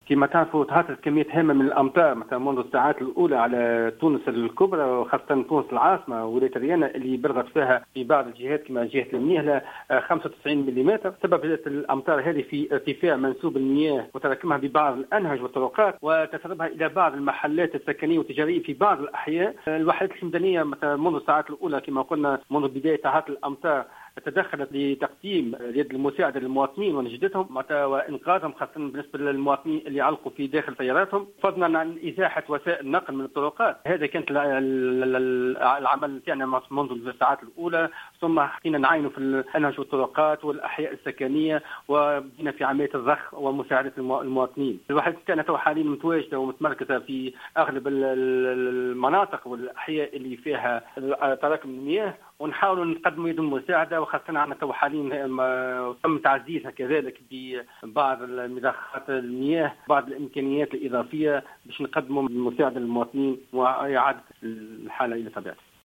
وأضاف في مداخلة له على "الجوهرة أف أم" أن أعوان الحماية المدنية تمركزوا في أغلب المناطق والأحياء بتونس العاصمة التي تشهد تراكما لمياه الأمطار، مؤكدا أيضا أنه تم توفير امكانيات ومعدّات إضافية لشفط المياه.